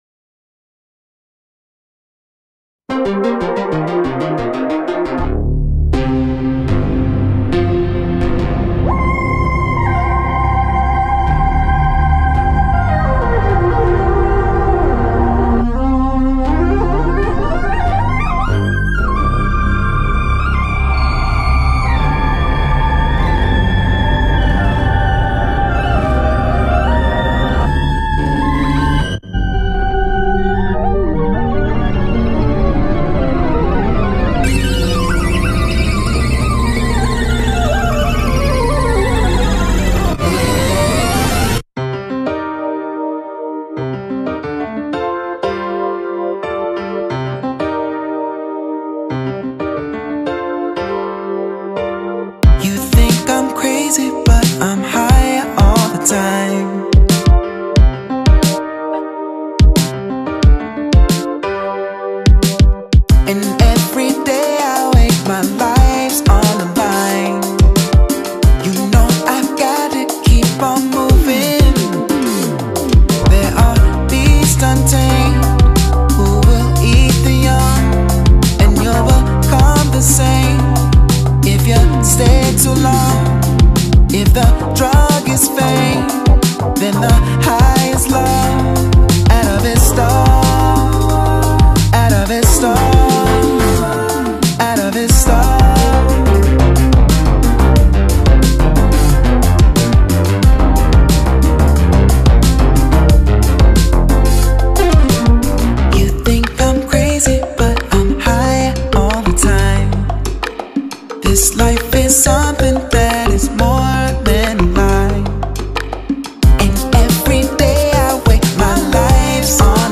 neo soul Experimental